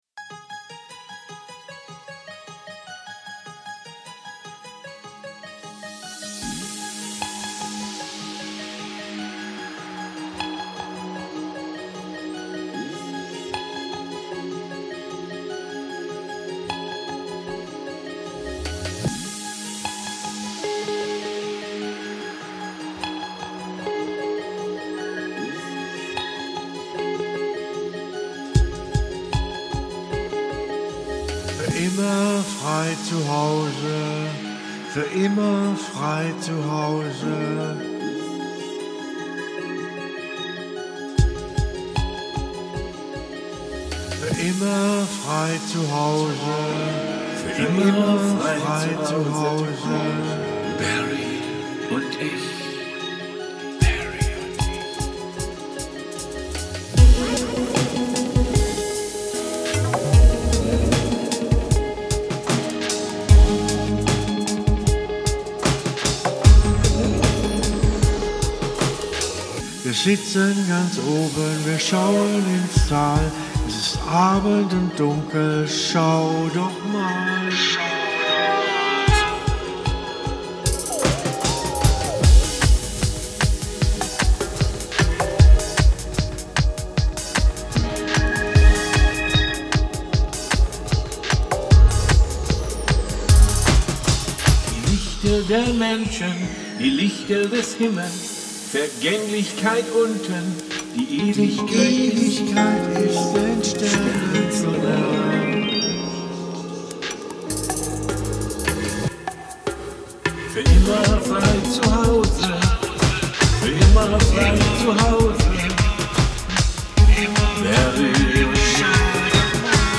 Guitar
Lyrics/Vocals